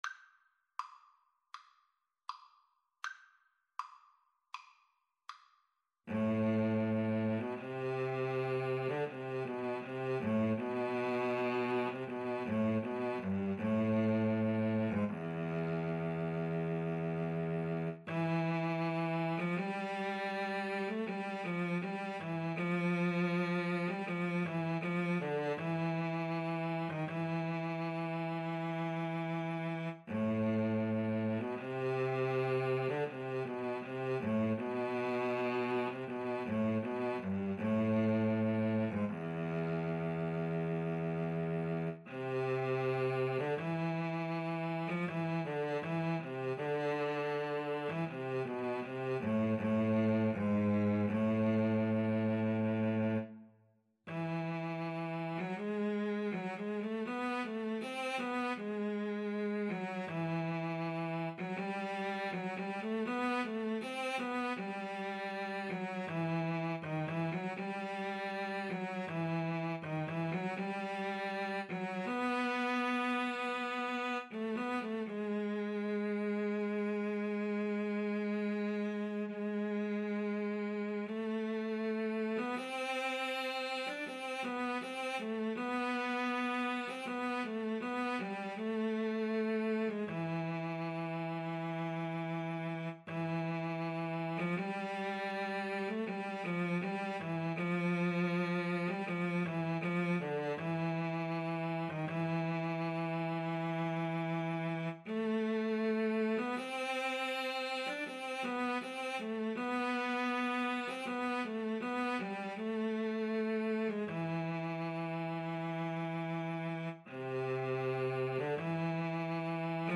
Andante
Classical (View more Classical Guitar-Cello Duet Music)